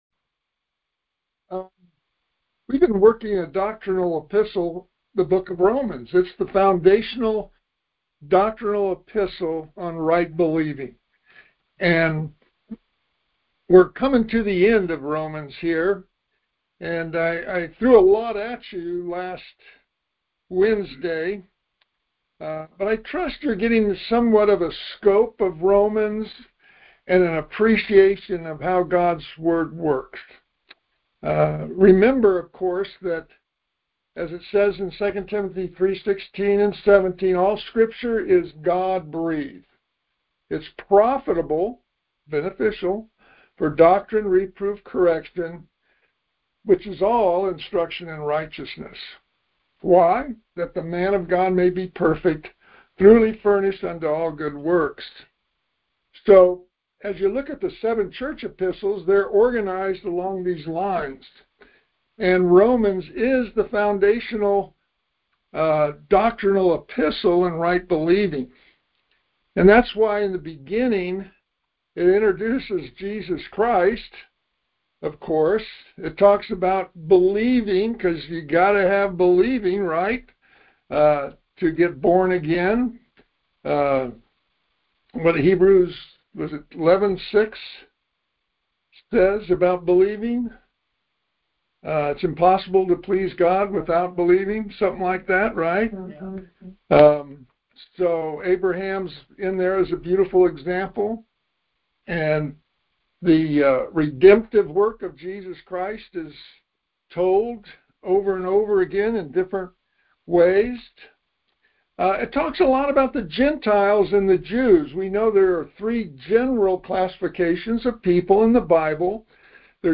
Details Series: Conference Call Fellowship Date: Wednesday, 04 February 2026 Hits: 15 Scripture: Romans 12-16 Play the sermon Download Audio ( 12.35 MB )